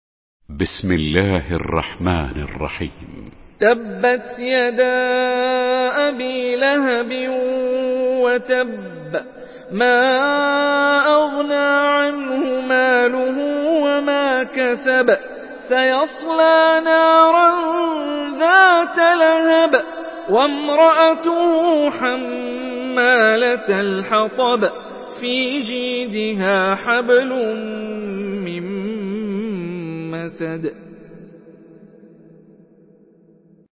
Surah Al-Masad MP3 in the Voice of Hani Rifai in Hafs Narration
Murattal Hafs An Asim